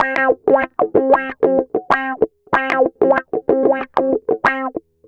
Track 13 - Clean Guitar Wah 07.wav